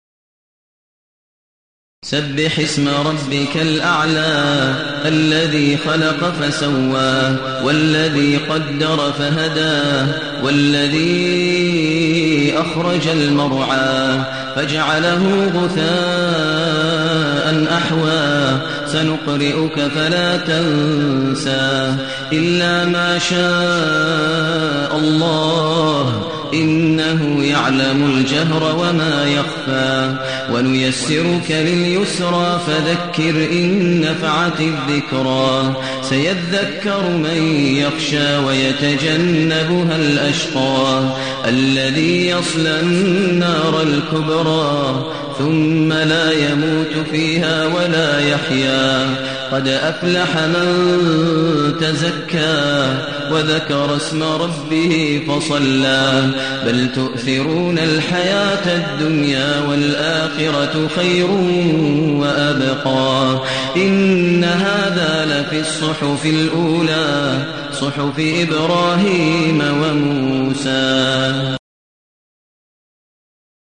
المكان: المسجد النبوي الشيخ: فضيلة الشيخ ماهر المعيقلي فضيلة الشيخ ماهر المعيقلي الأعلى